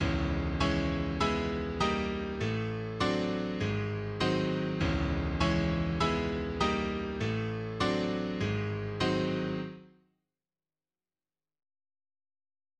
Два такта Сергея Рахманинова из Прелюдии до-диез минор с динамическим обозначением sffff. Верхние две системы исполняются правой рукой, нижние две — левой.